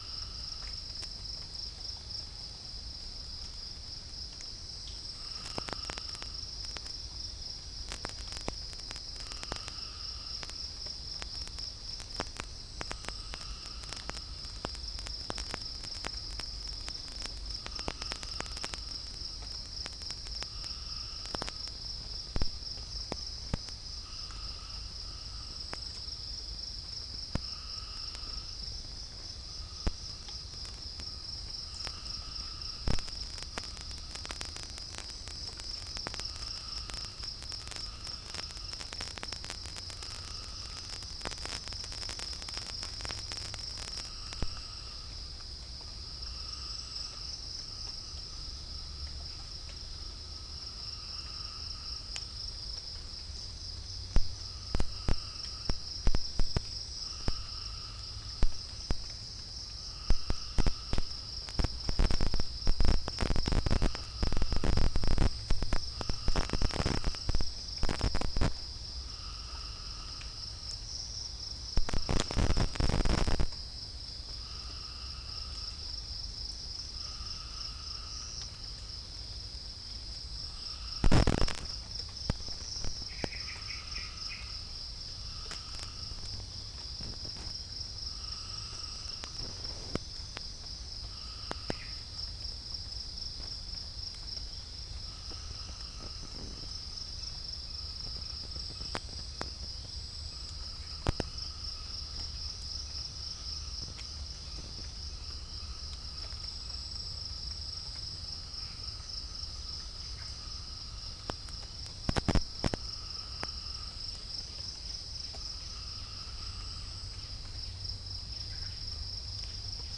Pycnonotus goiavier
Halcyon smyrnensis
Orthotomus sericeus
Orthotomus ruficeps
Prinia familiaris